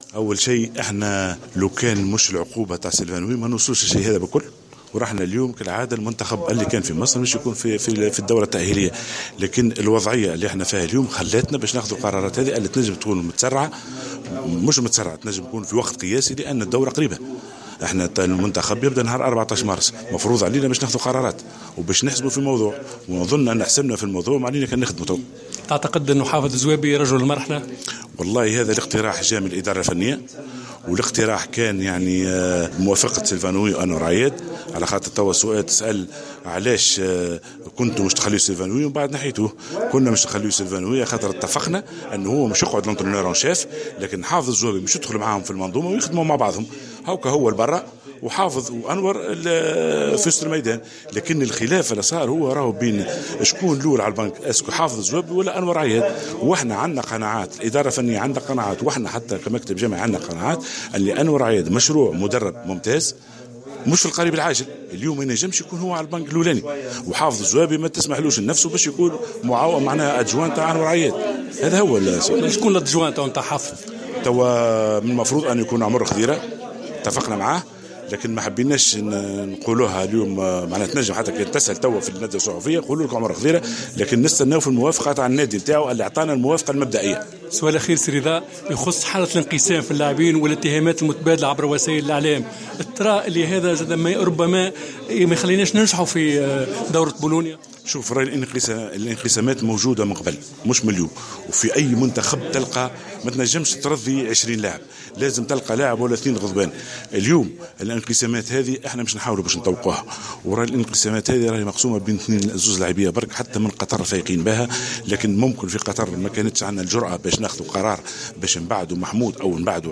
عقد أعضاء المكتب الجامعي لكرة اليد اليوم الثلاثاء 08 مارس 2016 ندوة صحفية لتوضيح القرارات التي كان قد أعلن عنها يوم أمس وتقديم المزيد من التفاصيل.